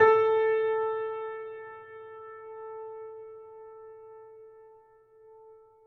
piano-sounds-dev
Steinway_Grand